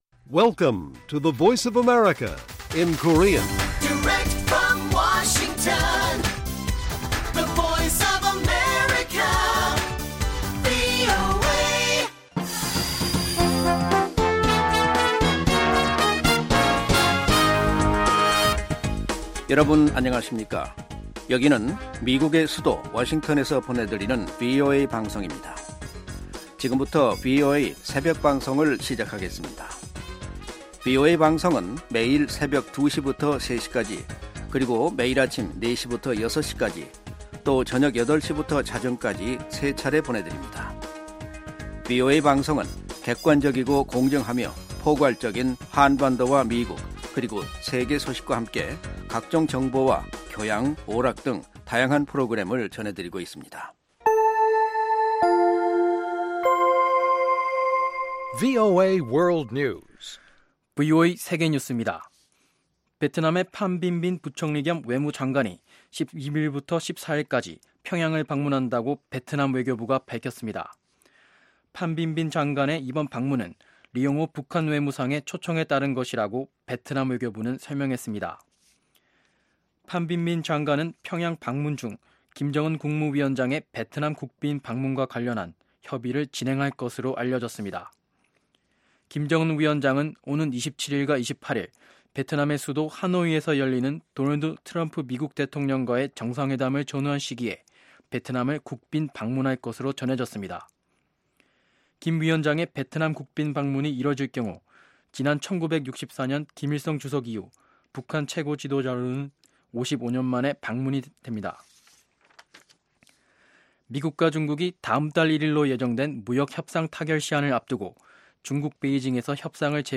VOA 한국어 '출발 뉴스 쇼', 2019년 2월 12일 방송입니다. 도널드 트럼프 미국 대통령은 2차 미-북 정상회담이 베트남 하노이에서 열릴 것이라고 발표했습니다. 미 하원 외교위원장이 2차 미-북 정상회담에 나서는 김정은 북한 국무위원장의 의도에 의문을 제기했습니다.